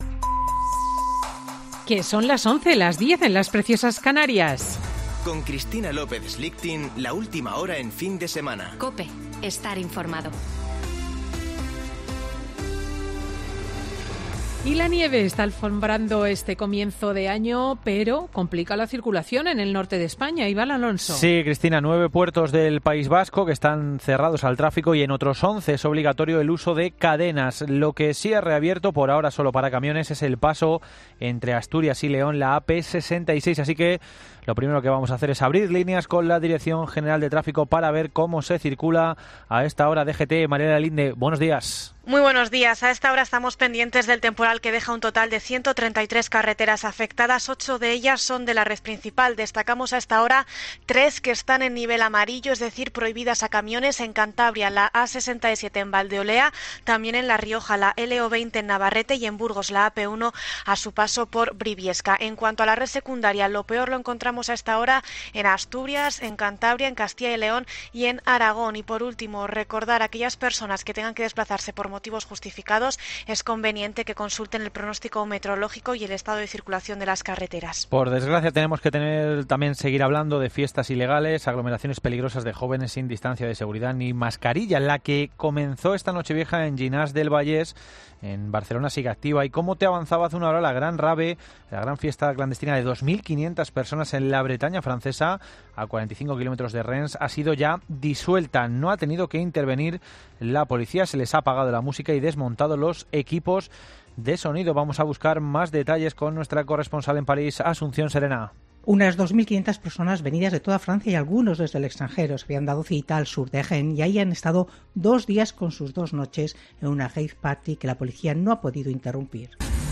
Boletín de noticias COPE del 2 de enero de 2021 a las 11.00 horas